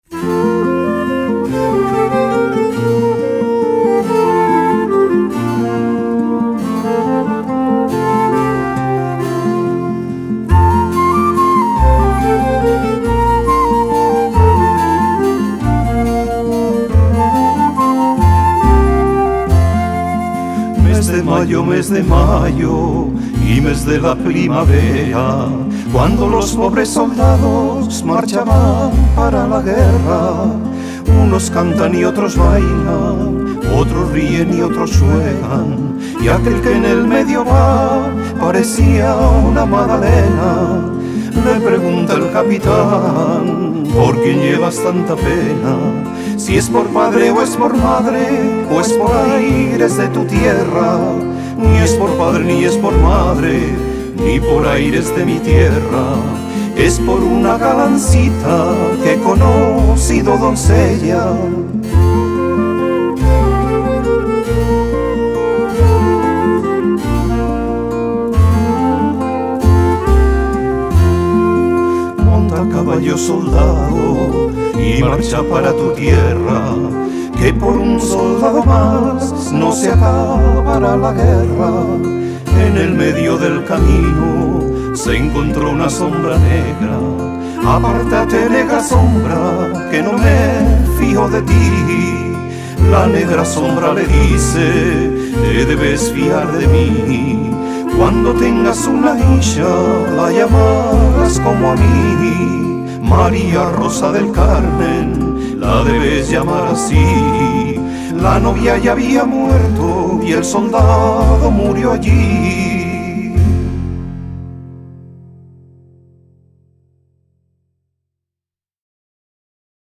Viola
Guitarra
Flauta y clarinete
Clave y bajo